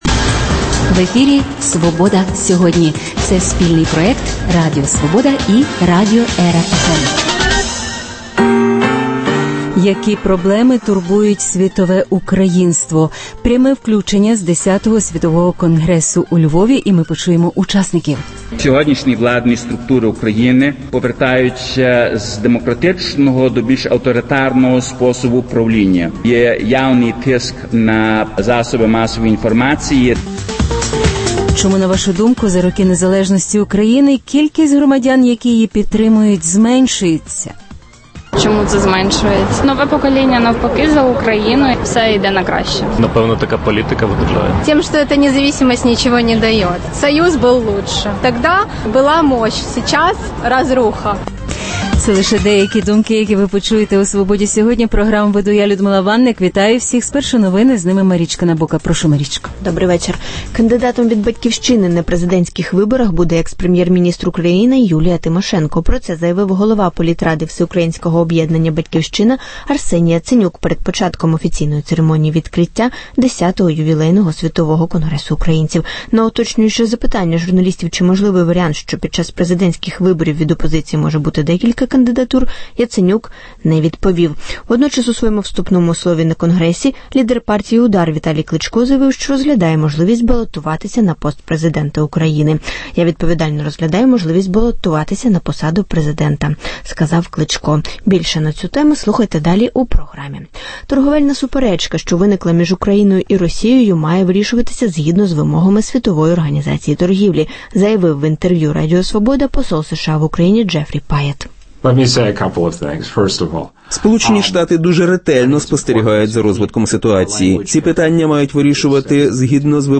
Пряме включення з Х Світового конгресу українців «Roshen» має намір змінювати географію експорту, а «Запоріжсталь» призупинила постачання продукції на територію Російської Федерації.